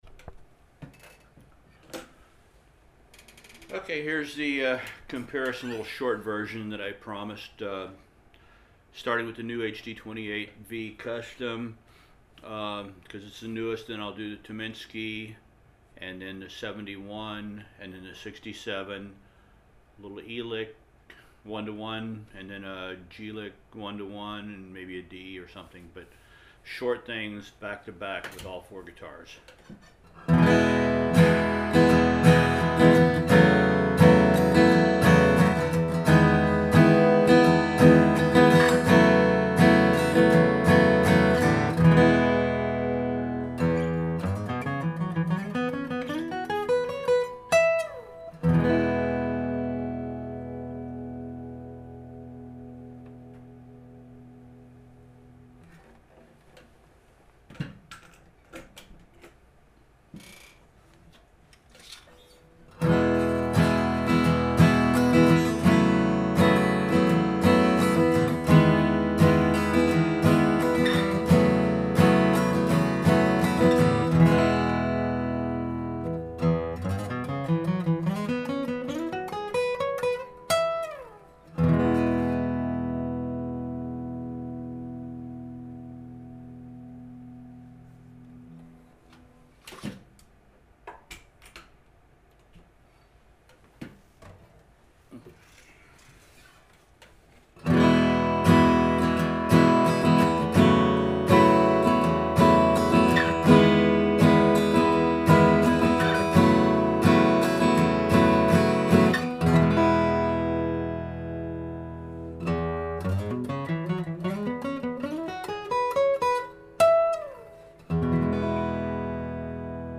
Четыре Martin Сравнение D-28
Я люблю варианты тональности и различия между моими четырьмя Martin D-28! :)))